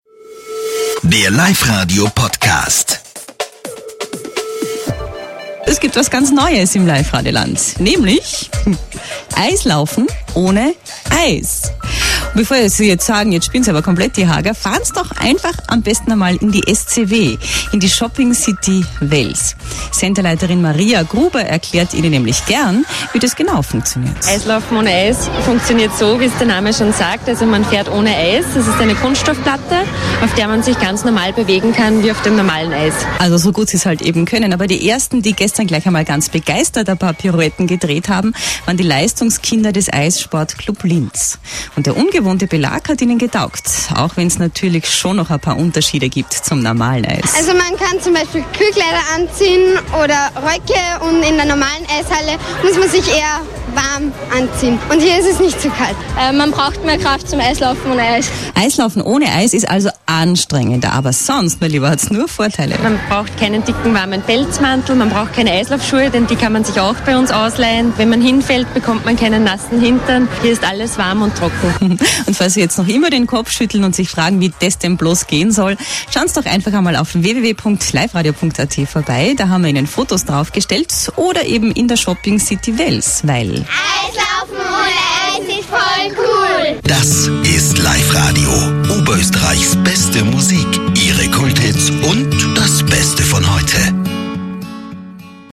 Bericht vom Life Radio als Podcast verfügbar (mp3 Dateigröße 1,5 MB)!